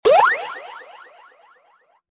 Video Game Controller